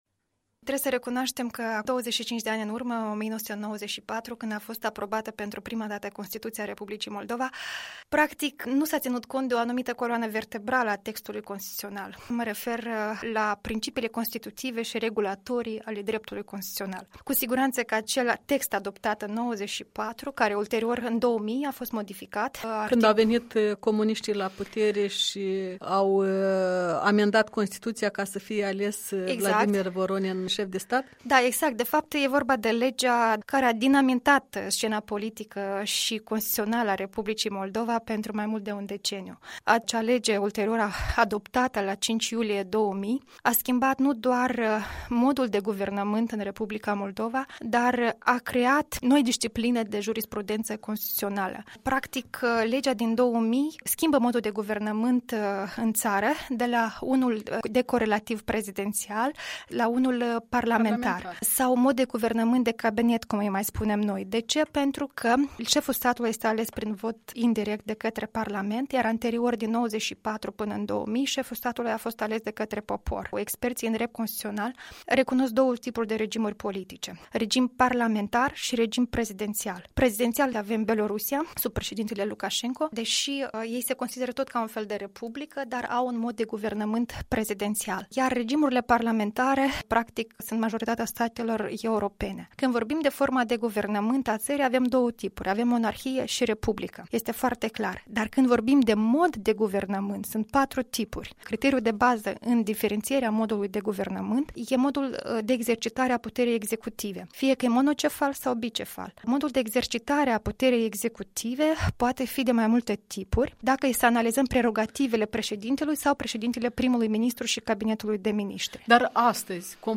Un interviu cu o expertă în drept constituțional și autoare a unui proiect de modicare a Constituției R. Moldova.